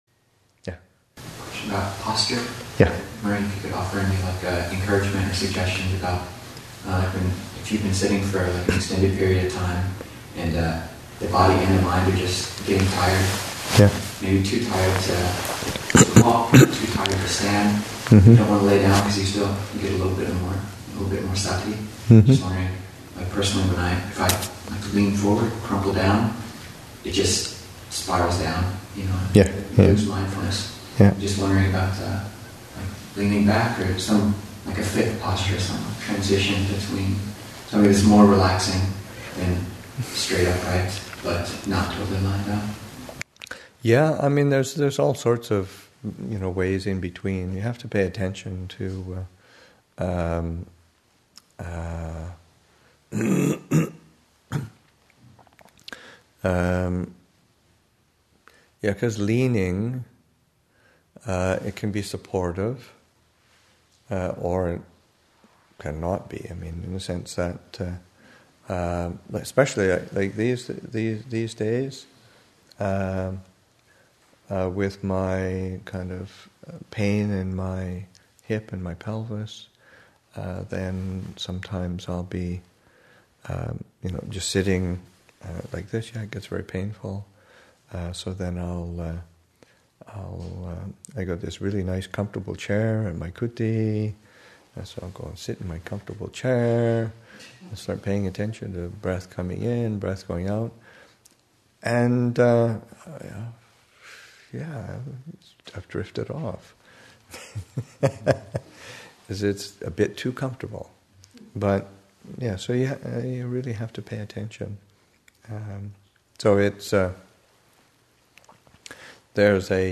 Mindfulness of Breathing, Session 2 – Oct. 26, 2014